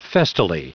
Prononciation du mot festally en anglais (fichier audio)
Prononciation du mot : festally